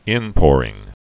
(ĭnpôrĭng)